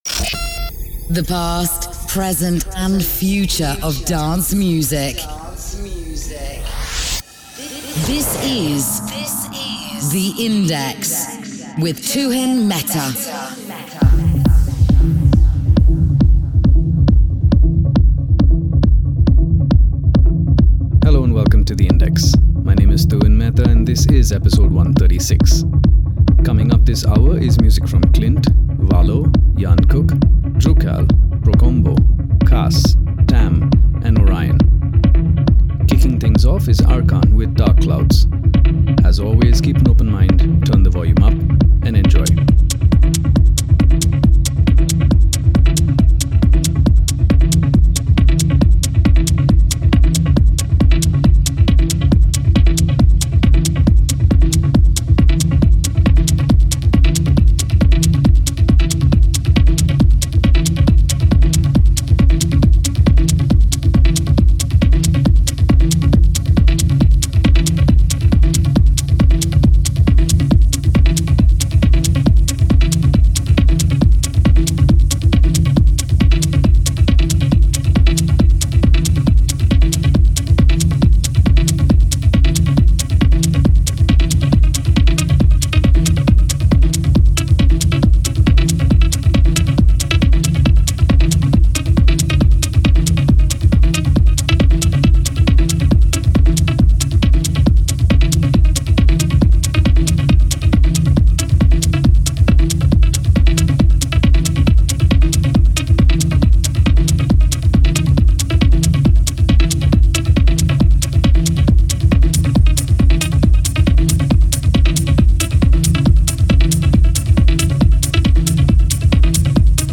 Techno
Dance